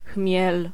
Ääntäminen
Ääntäminen France: IPA: [u.blɔ̃] Tuntematon aksentti: IPA: /hu.blɔ̃/ Haettu sana löytyi näillä lähdekielillä: ranska Käännös Ääninäyte Substantiivit 1. chmiel {m} Suku: m .